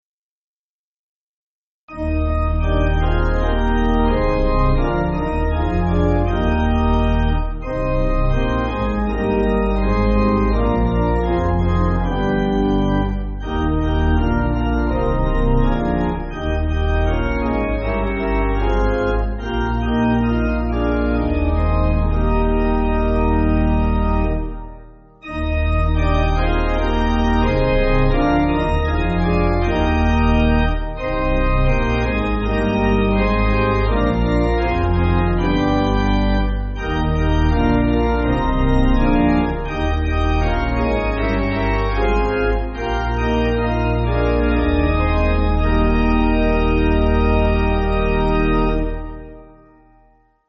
Organ
(CM)   2/Eb